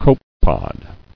[co·pe·pod]